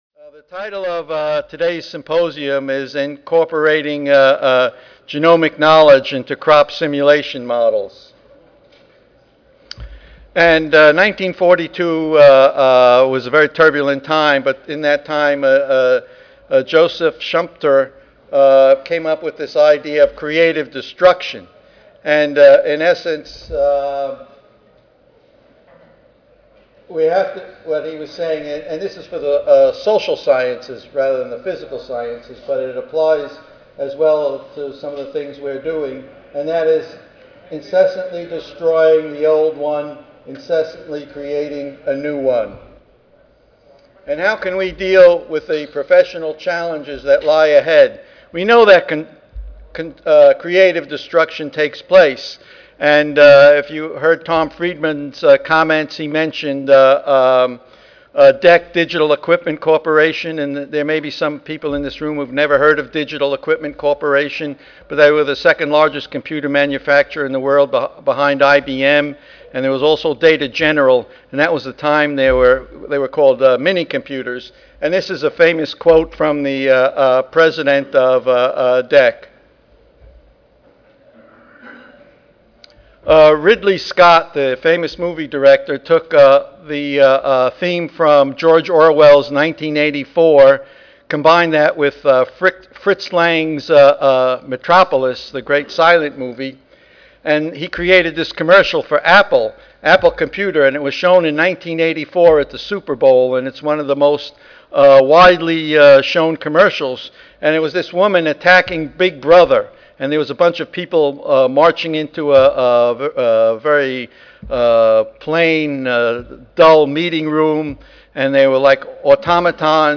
158-1 Introductory Remarks.
Audio File Recorded presentation